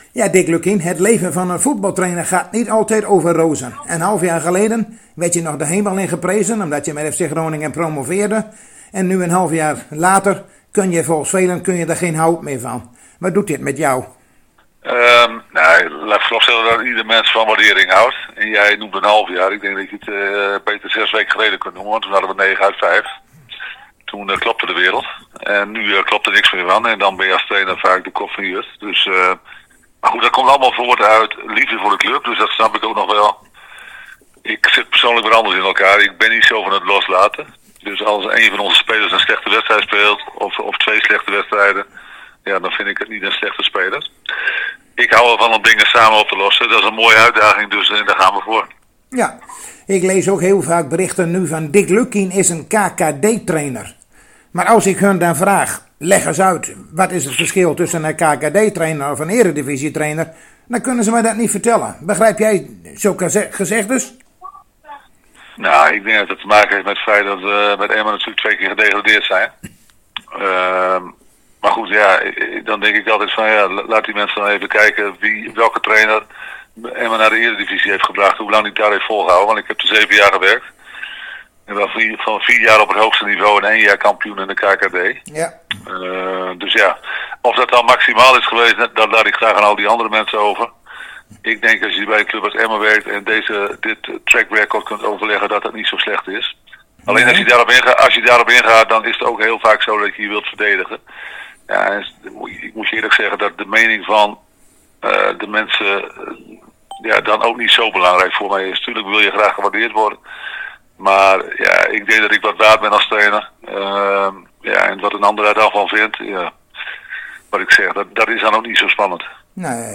Zojuist spraken wij weer met Dick Lukkien over de wedstrijd van morgen tegen Sparta Rotterdam en die is te beluisteren via de blauwe link en ook plaatsen wij hier een paar foto's van de training van hedenmorgen.